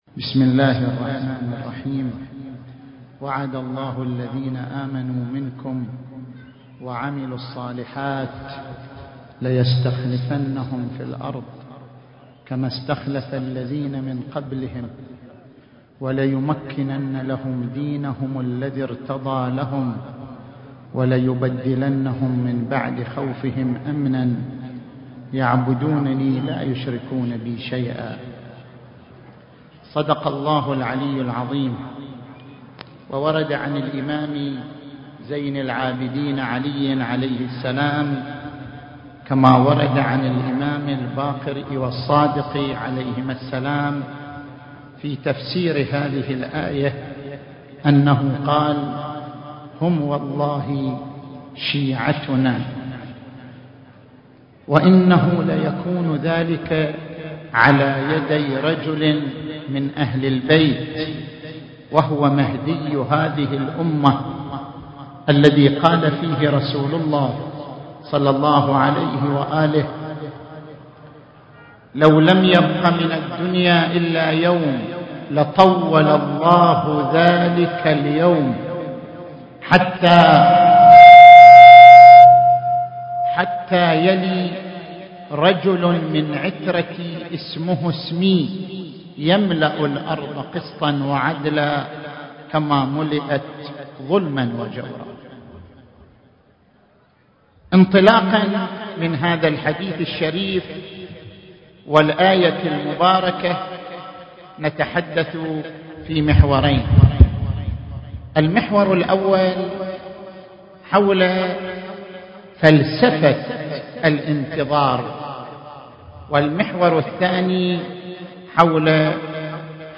في احتفال الامام المنتظر عليه السلام